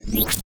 UIMvmt_Futuristic Power PickUp 03.wav